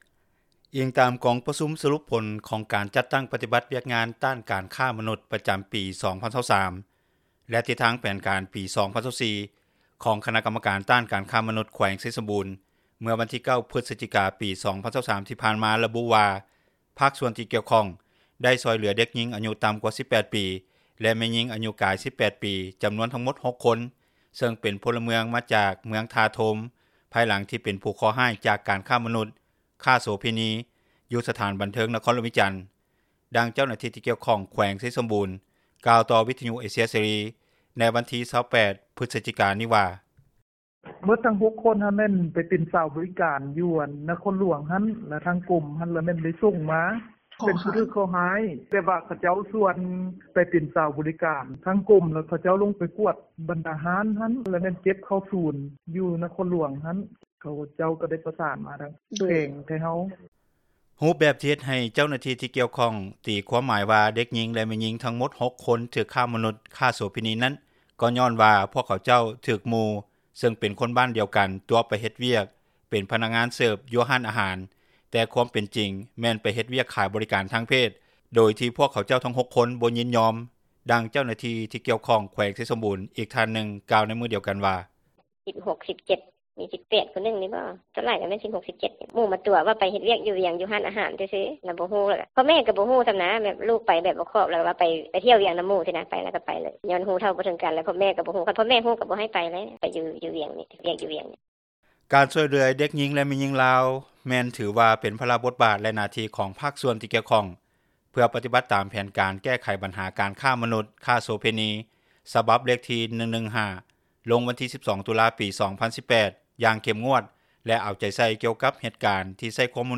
ດັ່ງເຈົ້າໜ້າທີ່ ທີ່ກ່ຽວຂ້ອງແຂວງ ໄຊສົມບູນ ກ່າວຕໍ່ວິທຍຸເອເຊັຽເສຣີ ໃນວັນທີ 28 ພຶສຈິການີ້ວ່າ:
ດັ່ງເຈົ້າໜ້າທີ່ ທີ່ກ່ຽວຂ້ອງ ແຂວງໄຊສົມບູນ ອີກທ່ານນຶ່ງກ່າວໃນມື້ດຽວກັນວ່າ: